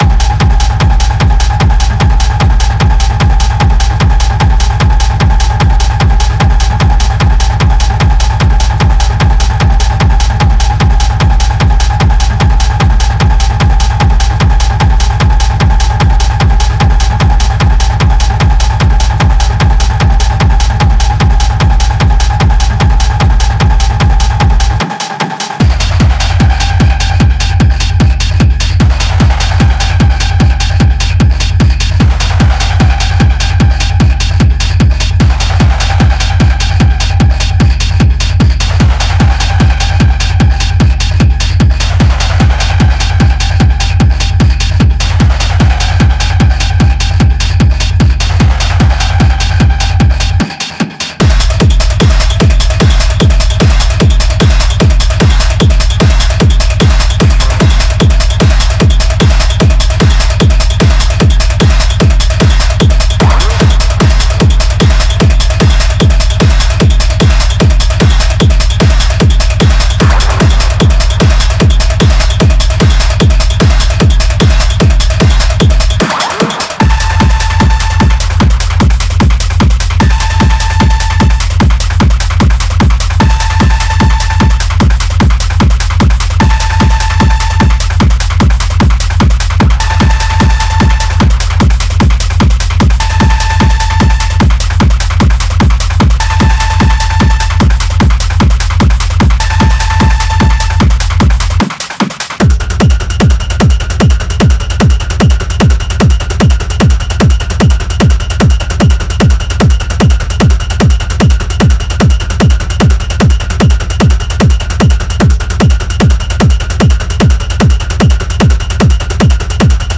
Genre:Industrial Techno
デモサウンドはコチラ↓